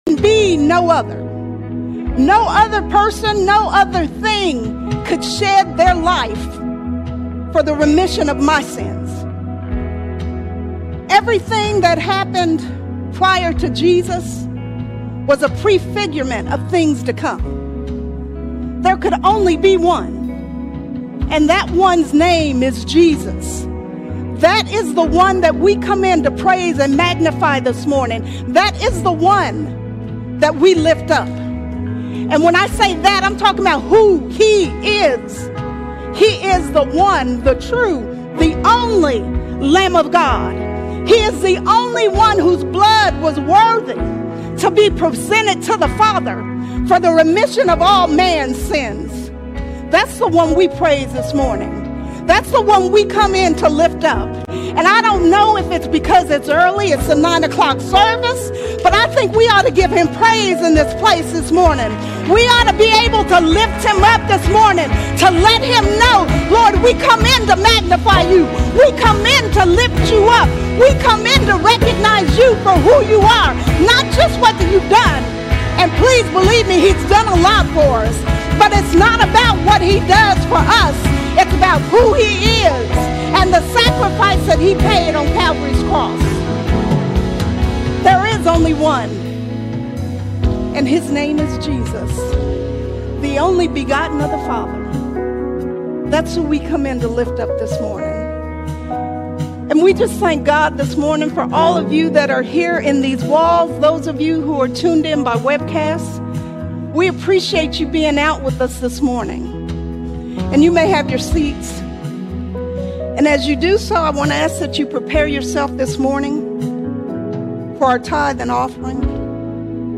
15 March 2026 Series: Sunday Sermons All Sermons A Hangry Nation A Hangry Nation We live in a hangry nation, always consuming, never satisfied.